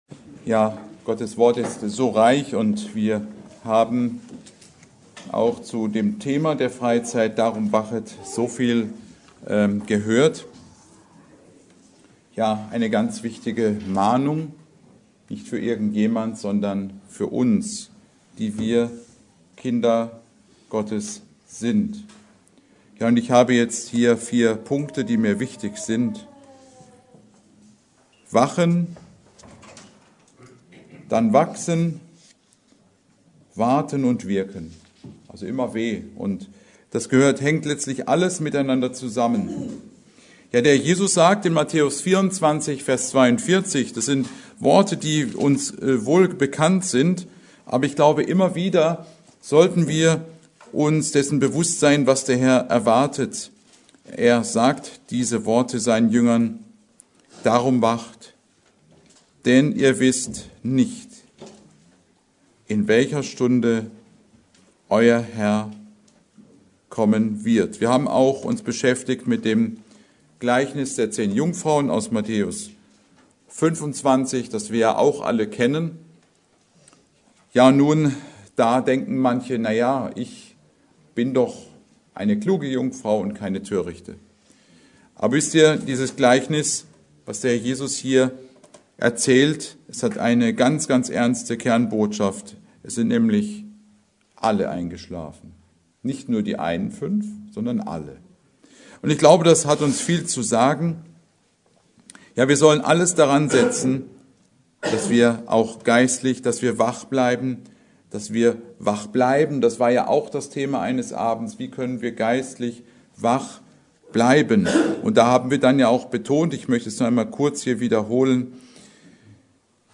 Predigt: Darum wacht!